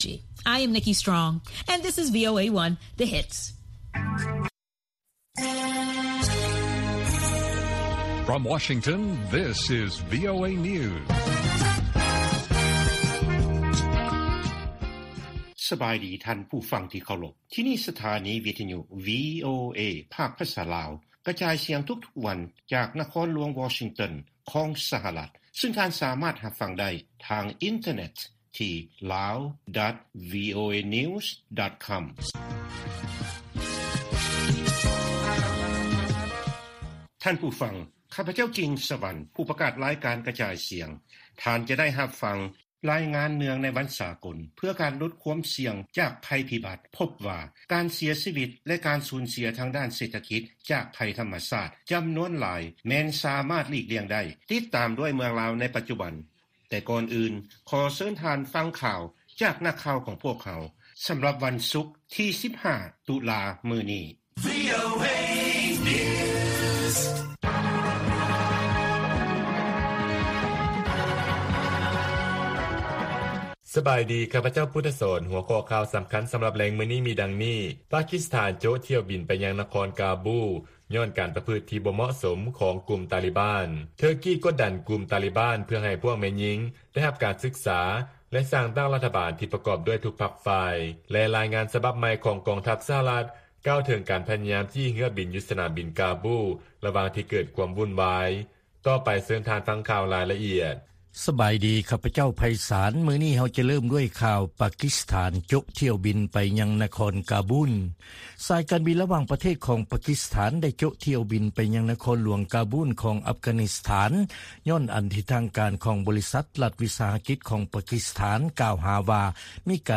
ລາຍການກະຈາຍສຽງຂອງວີໂອເອ ລາວ: ທຸລະກິດບໍລິການ ກວ່າ 530 ແຫ່ງ ໃນນະຄອນວຽງຈັນຕ້ອງຢຸດດຳເນີນກິດຈະການ ເພາະຜົນກະທົບຈາກໄວຣັສໂຄວິດ-19